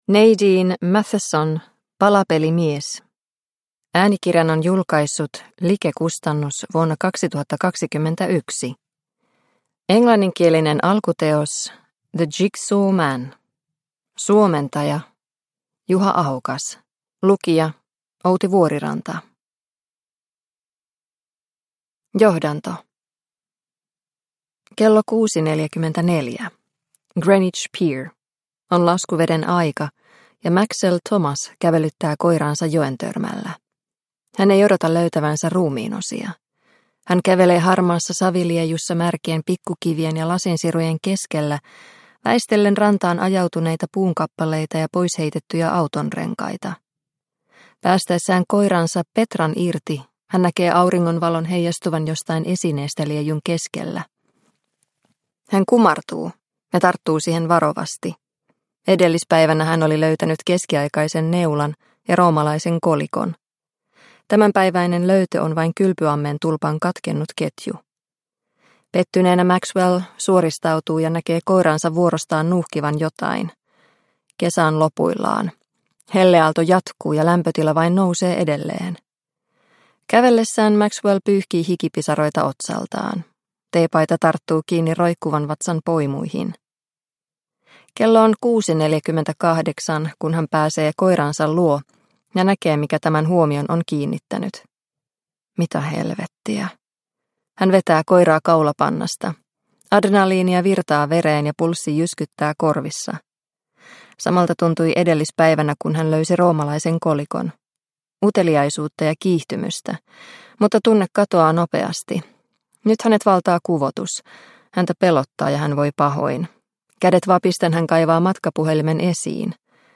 Palapelimies – Ljudbok – Laddas ner